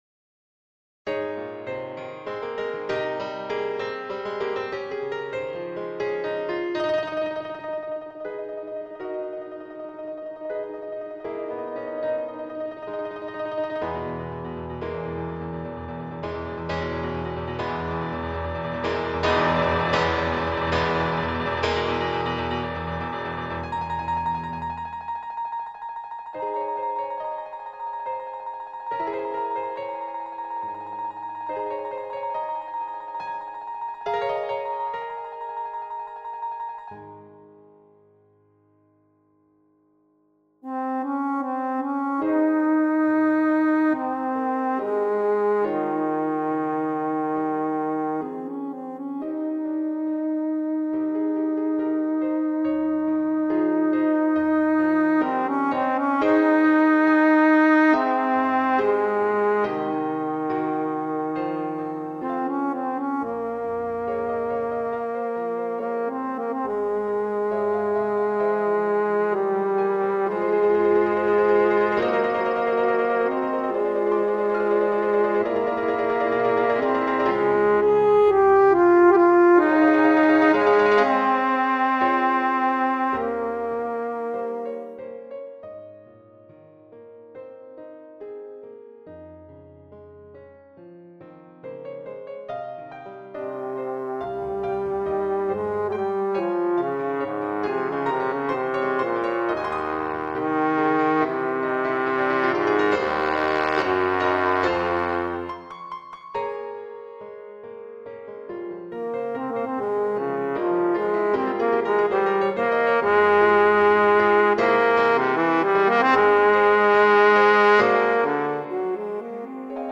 Single Movement.